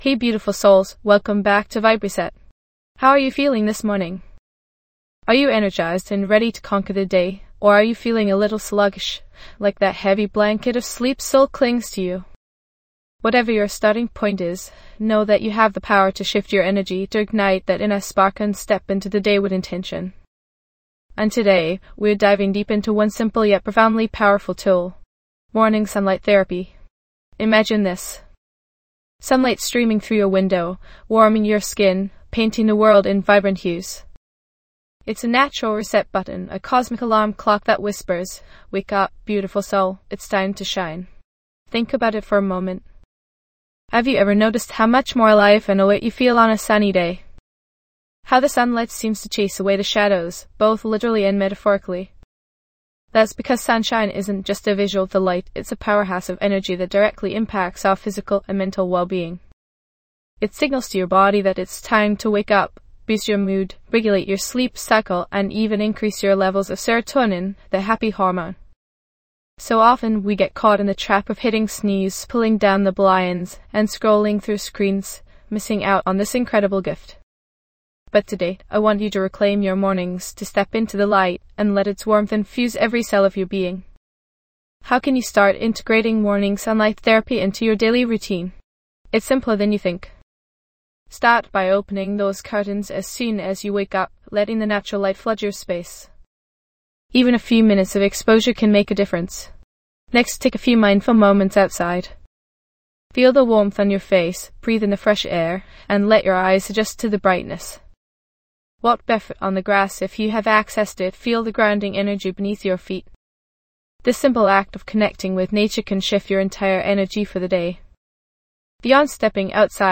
'Vibe Reset: Micro Meditations for Any Mood' offers bite-sized meditations designed to help you navigate your emotions and find peace in the midst of chaos. With short, accessible guided sessions tailored for various moods, this podcast provides a quick escape from daily pressures and helps you cultivate inner calmness and clarity.